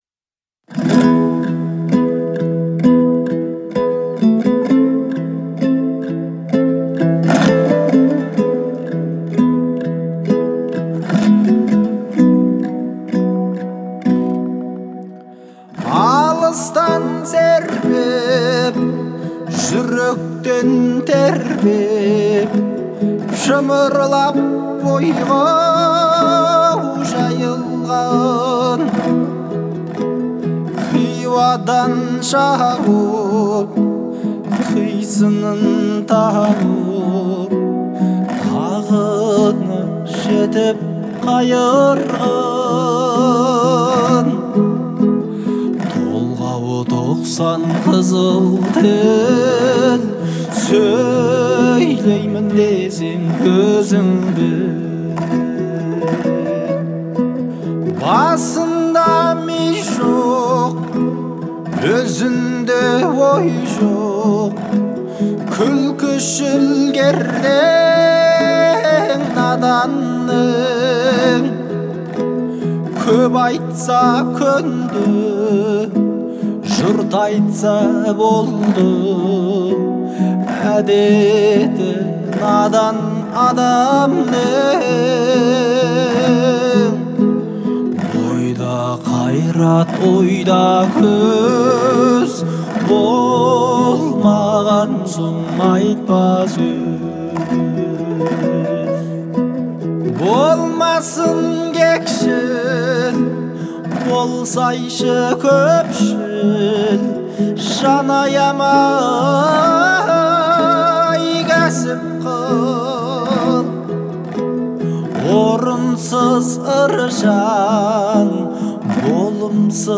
казахской певицы